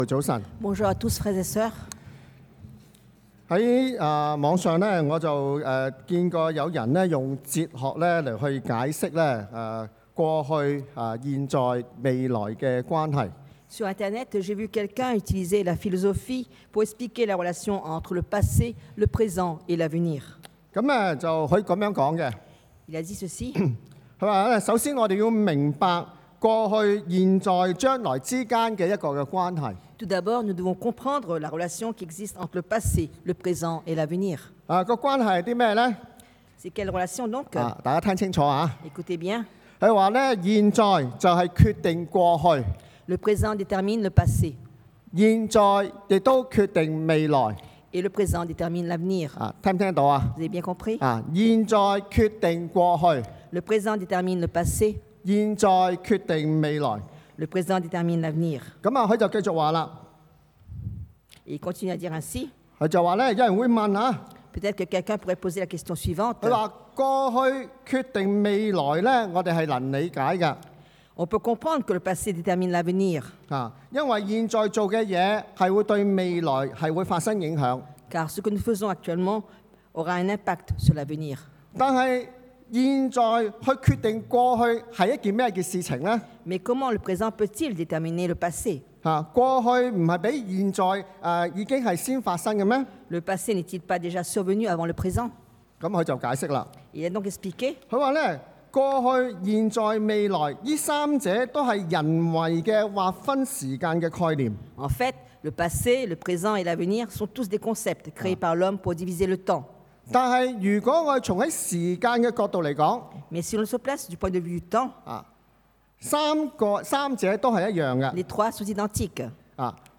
Le présent éternel et l’avenir 永恆的現在與未來 – Culte du dimanche